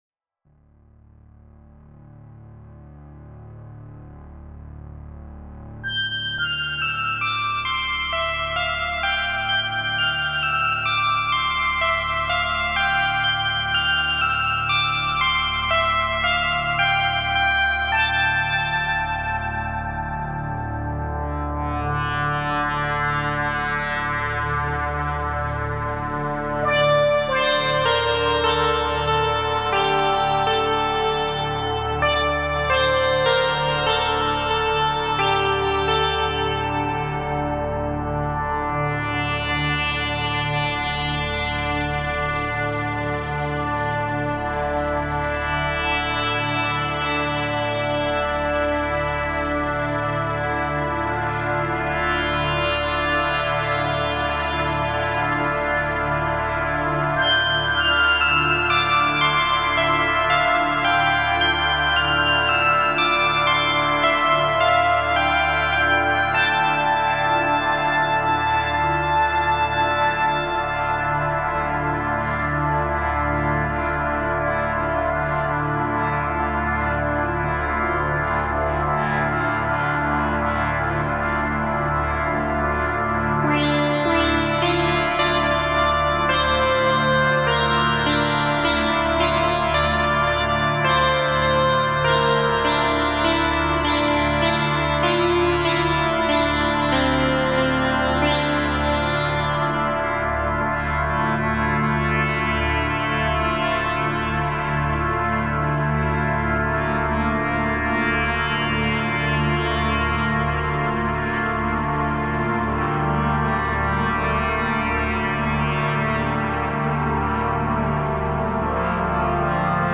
Polyend Synth (2024 erschienen)
Man kann jedenfalls gleichzeitig drei verschiedene Instrumente, die insgesamt 8-fach polyphon sein können, spielen.